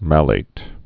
(mălāt, mālāt)